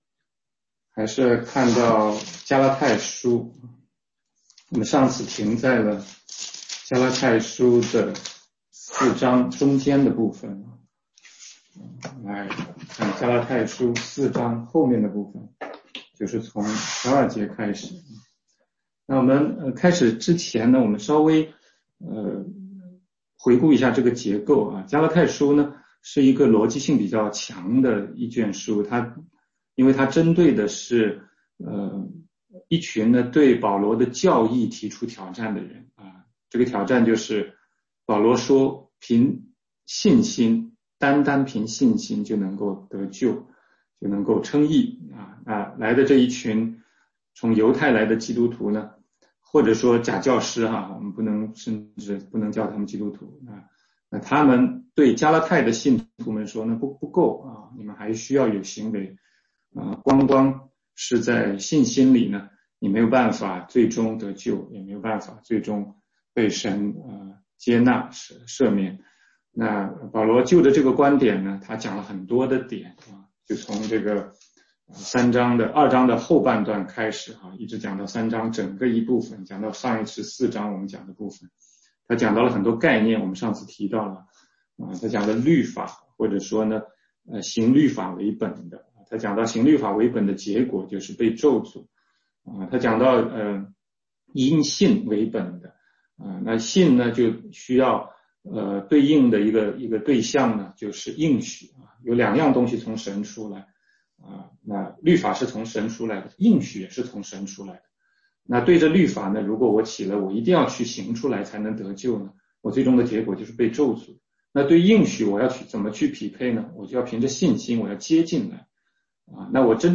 16街讲道录音 - 加拉太书4章12-20节：要小心“今生的骄傲”